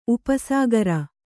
♪ upa sāgara